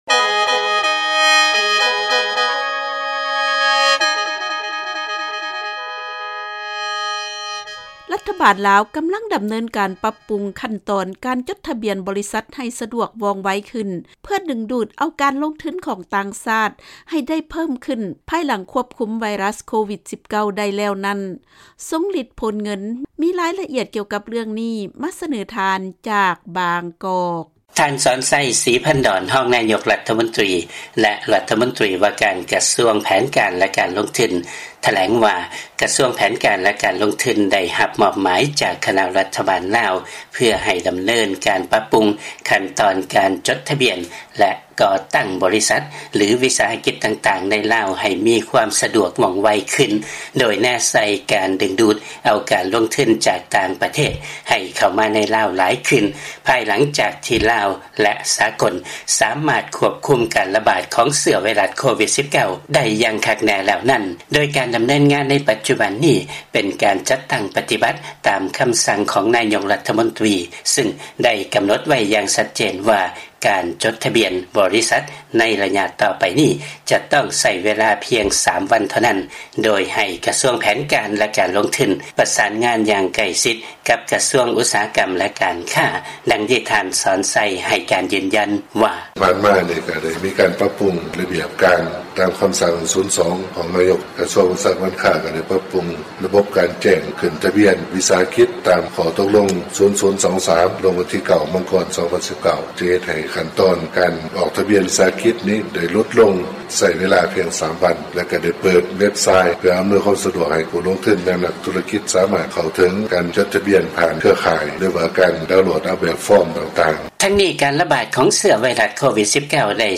ເຊີນຟັງລາຍງານ ລັດຖະບານລາວ ເລັ່ງປັບປຸງຂັ້ນຕອນ ການຈົດທະບຽນ ບໍລິສັດ ໃຫ້ສະດວກວ່ອງໄວຂຶ້ນ ເພື່ອດຶງເອົາການລົງທຶນຂອງຕ່າງຊາດ